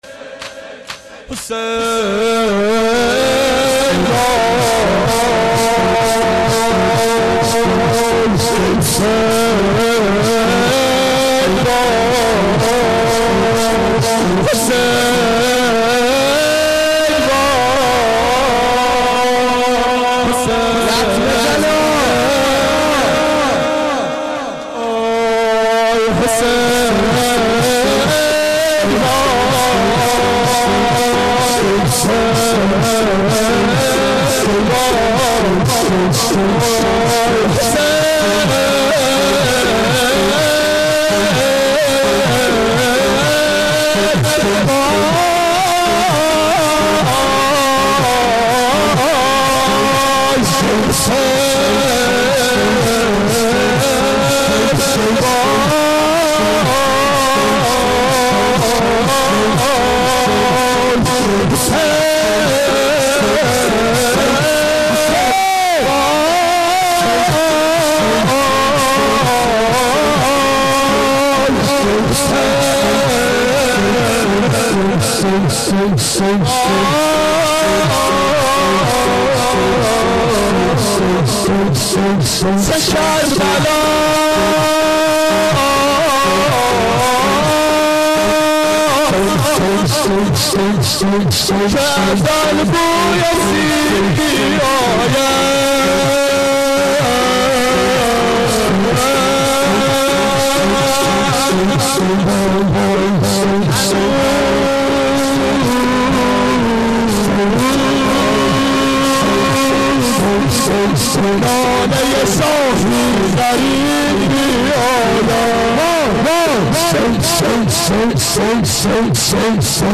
شب سوم محرم 88 گلزار شهدای شهر اژیه